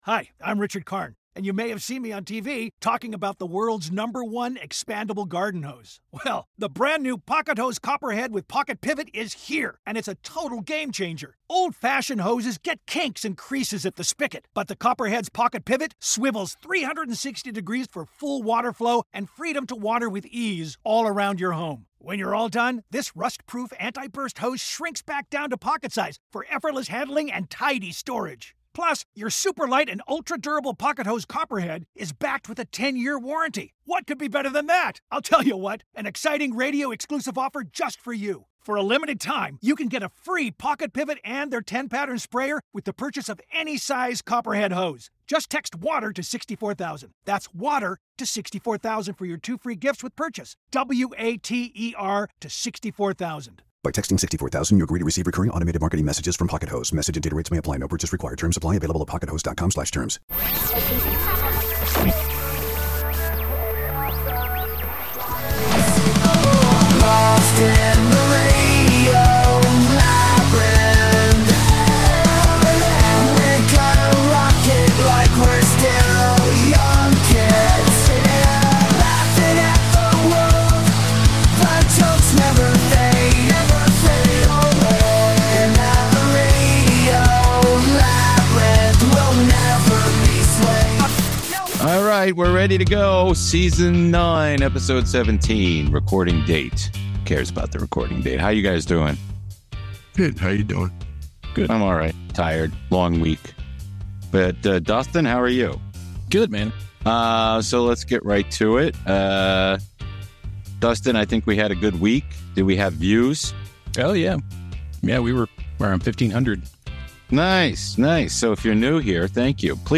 Facebook Twitter Headliner Embed Embed Code See more options Dive deep into the labyrinth of pop culture with the Radio Labyrinth crew as they dissect the latest stories in this week's episode!